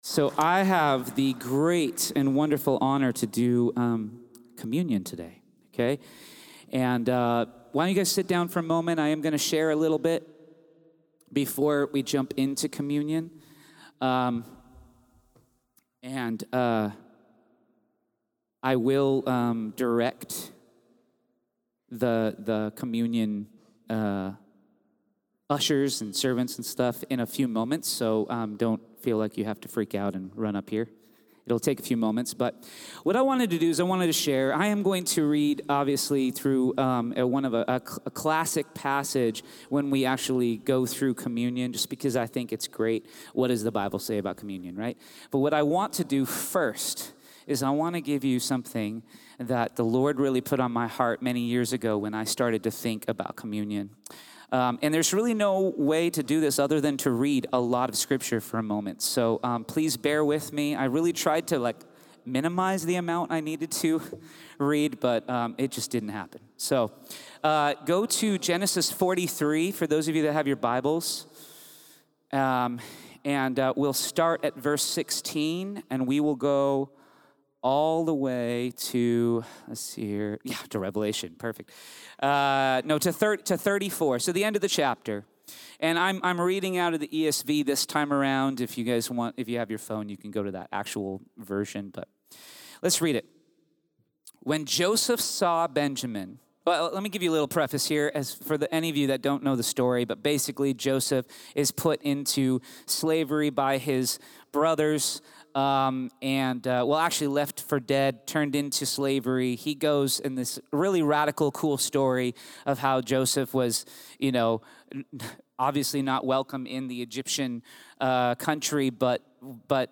Series: 2024 West Coast Worship Conference
Campus: Calvary Chapel Chino Valley